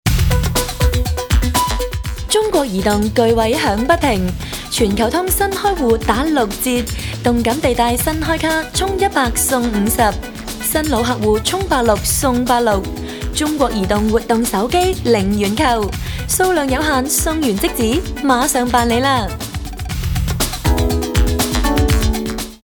女粤8_广告_手机通信_中国移动_欢快.mp3